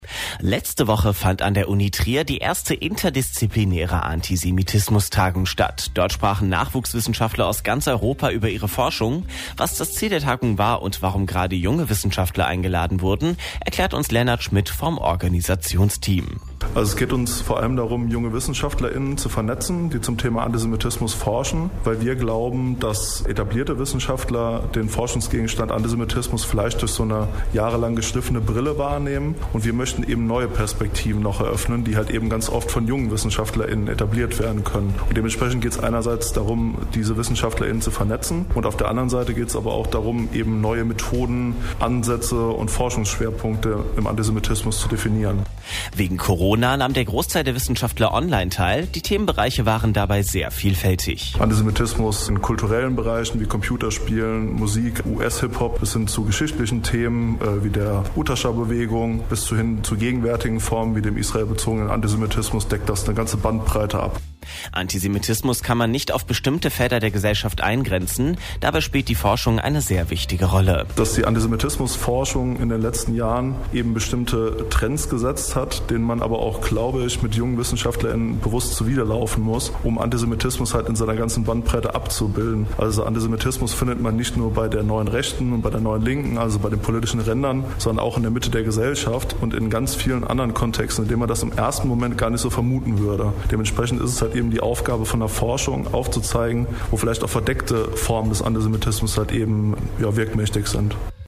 iia_trier_radiobeitrag_cityradiotrier.mp3